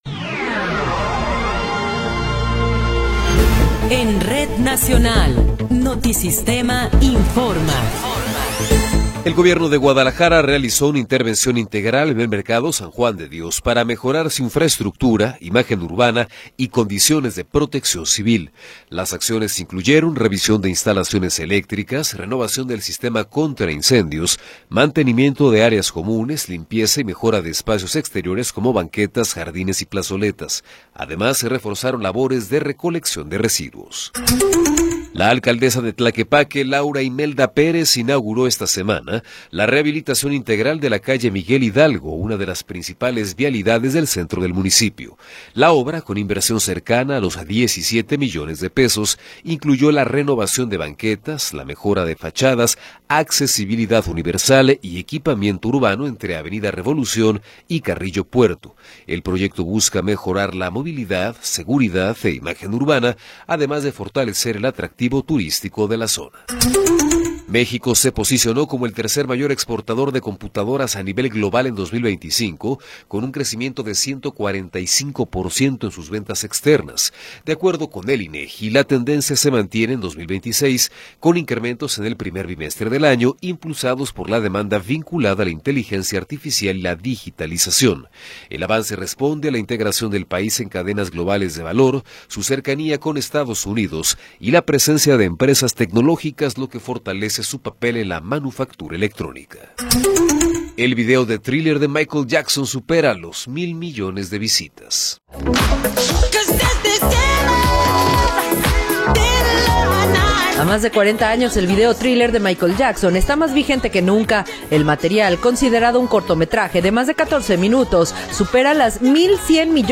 Noticiero 19 hrs. – 18 de Abril de 2026
Resumen informativo Notisistema, la mejor y más completa información cada hora en la hora.